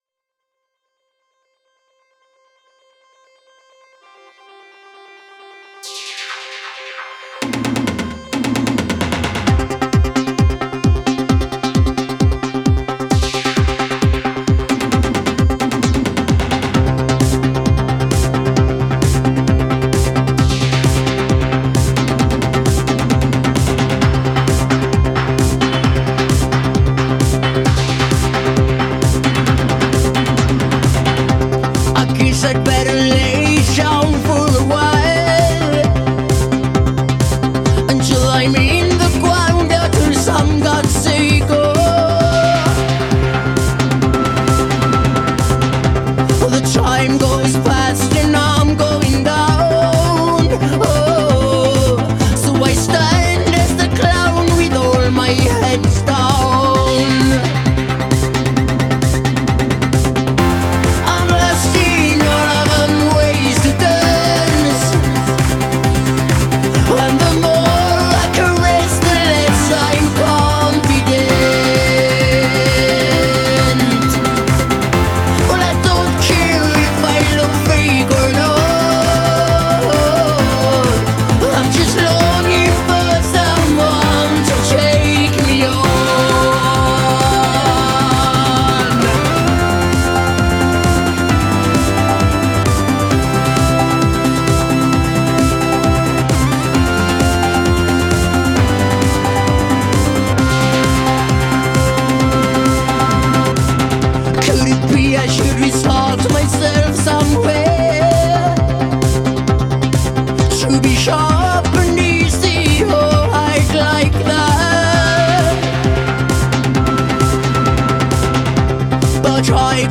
Swedish synthpop duo
Swedish new-wave/electro duo